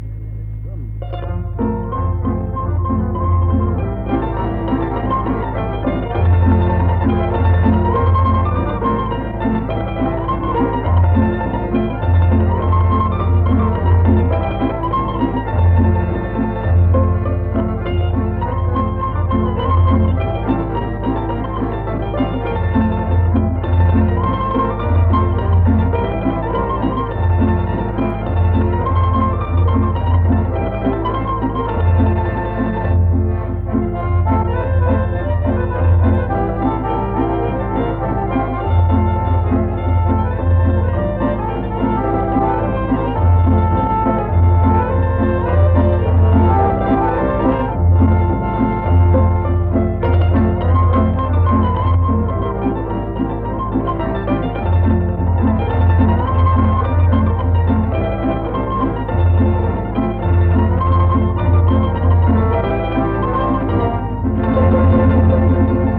Напомена: Инструментална верзија песме.
Инструментална и инструментално-певана музика
тамбурашки оркестар